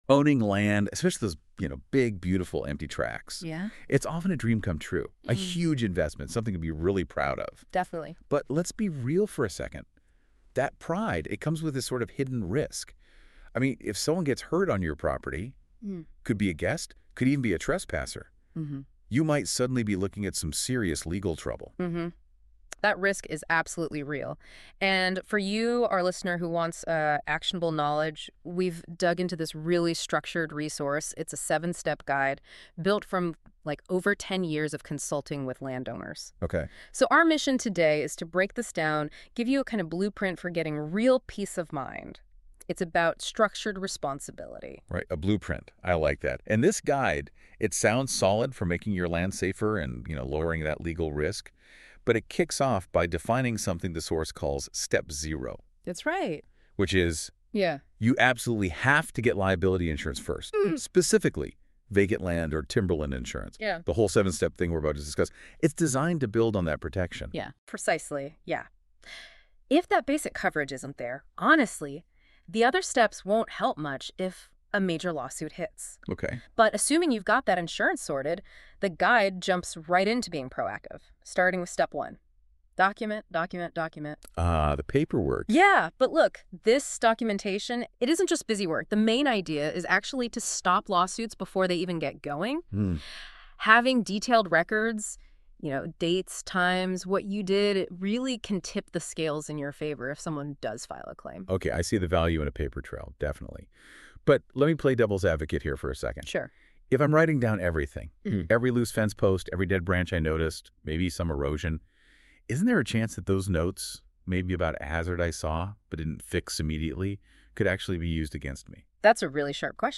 AI generated summary The provided audio excerpts focus on guiding landowners through a structured, proactive system for mitigating the significant legal and safety risks associated with owning large or vacant property. The core of the discussion is a seven-step plan designed to establish peace of mind, beginning with the mandatory requirement of securing specific vacant land liability insurance (Step Zero).